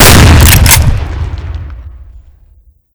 mp133_shoot2.ogg